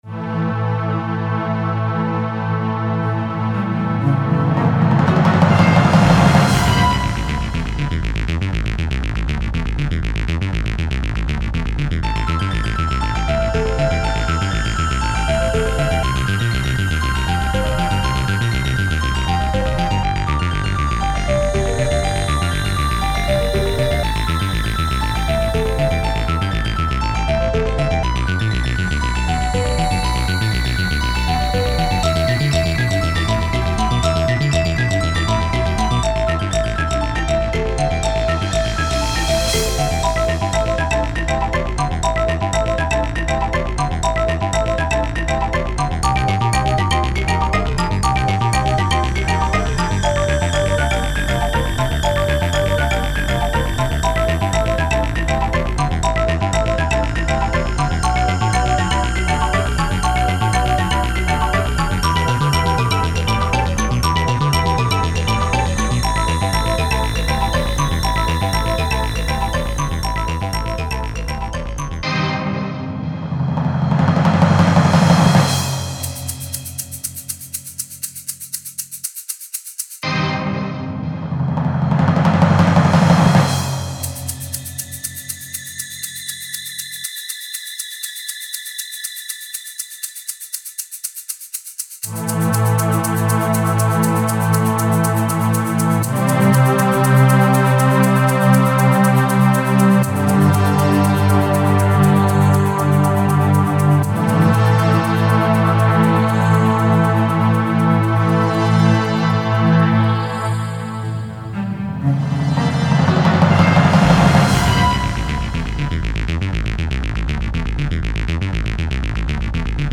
Ηλεκτρική Κιθάρα
Ακουστική Κιθάρα